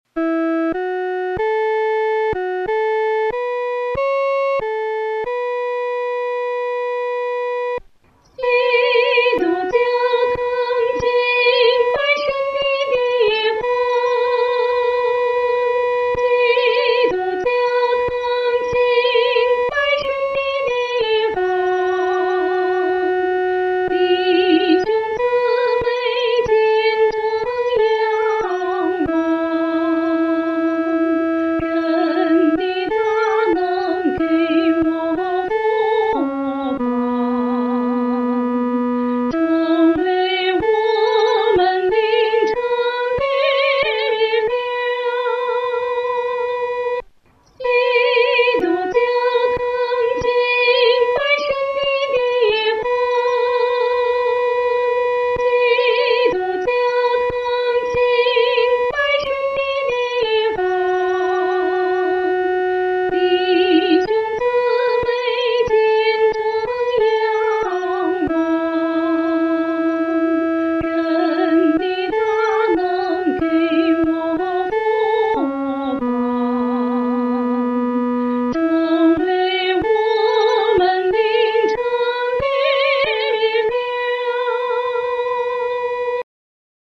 女高
这首诗歌宜用不快的中速来弹唱，声音要饱满。